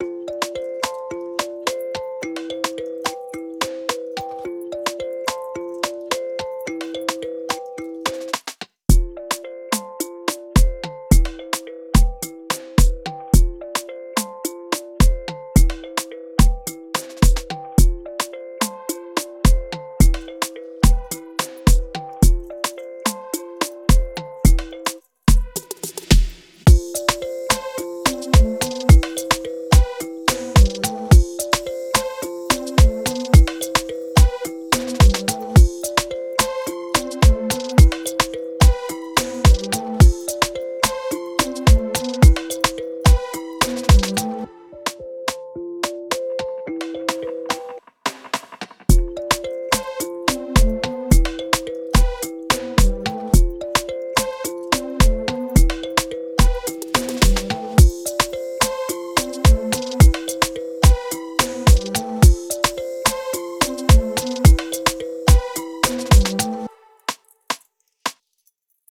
premade afrobeat loops and samples